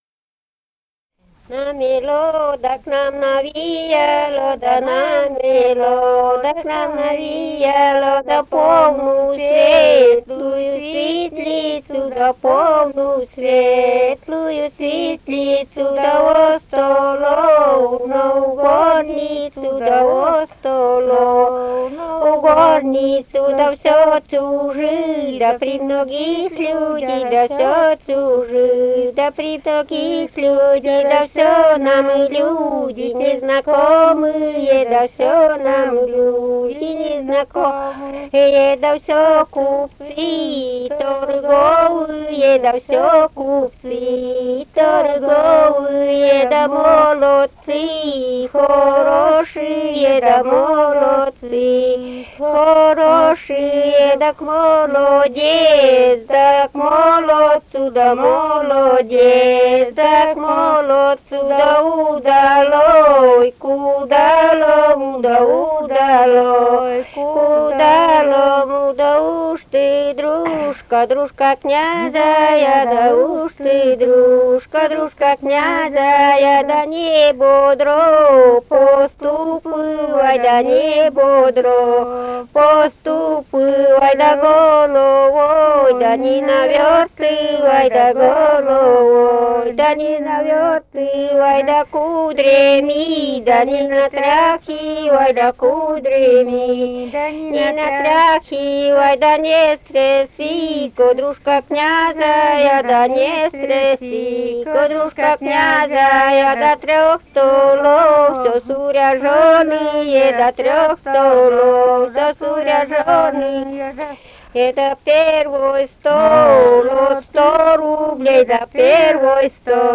«Намело, да к нам навеяло» (свадебная).